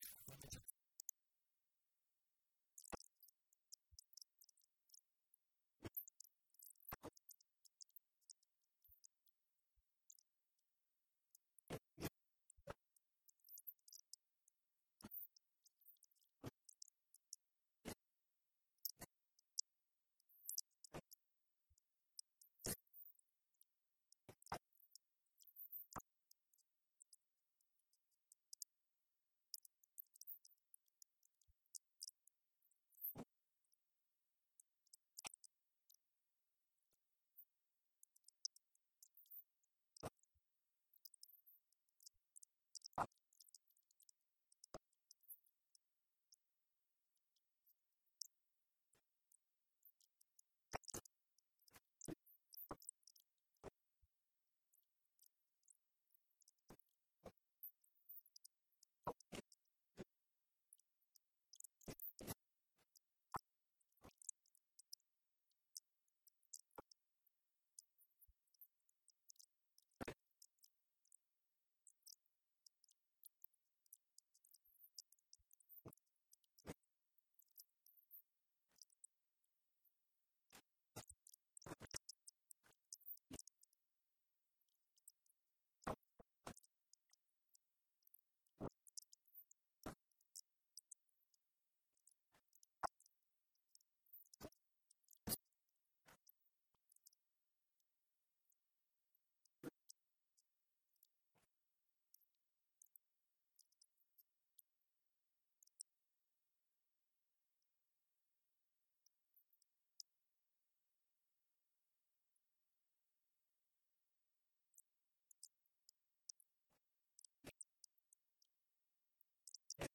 Audiência Pública nº 7/2023